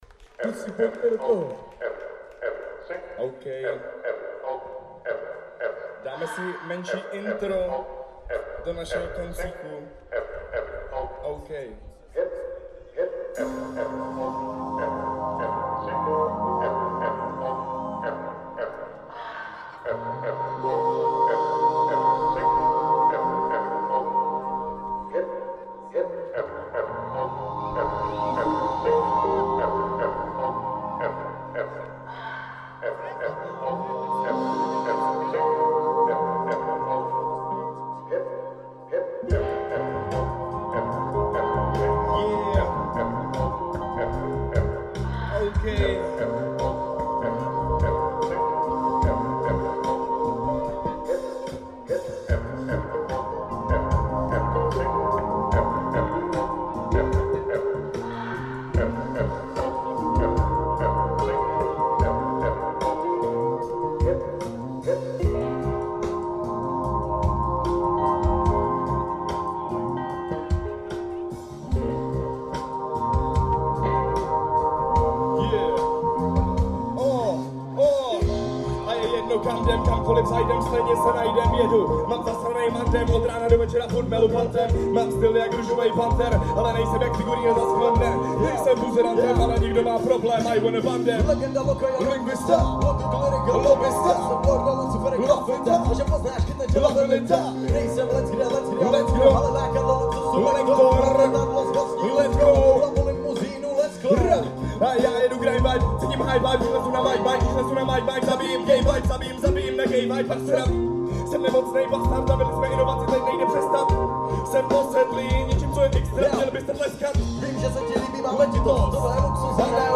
Sobotní live jam na úvod koncertu...jednoduchý precl (nejsem preclař, ale na tohle mi to sedí moc)
.. čest bejku, je to cítit že Tě to baví a že jsi v tomhle groove ja ryba ve vodě, pěkná houpačka, líbí se mi to .. jste super (: